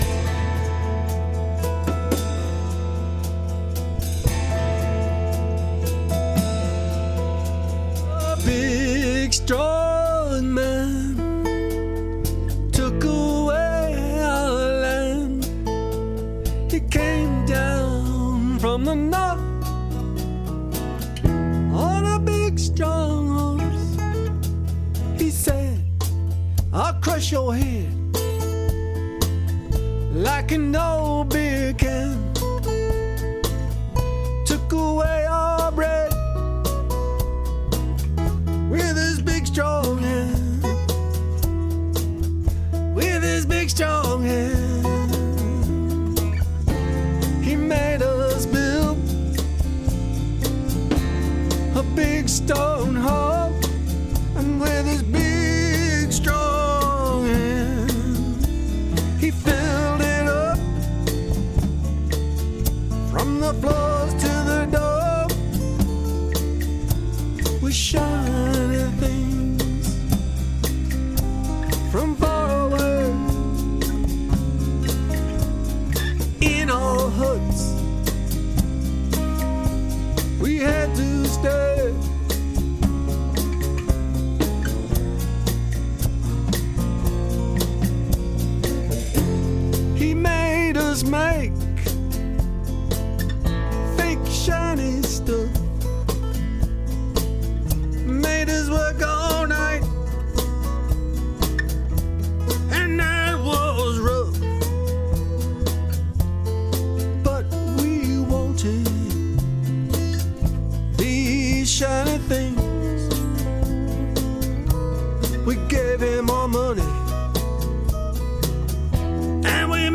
I think the vocal can be clearer.